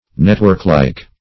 Search Result for " networklike" : Wordnet 3.0 ADJECTIVE (1) 1. having a network of veins or ribs ; The Collaborative International Dictionary of English v.0.48: networklike \net"work*like`\ adj. having a network of veins or ribs.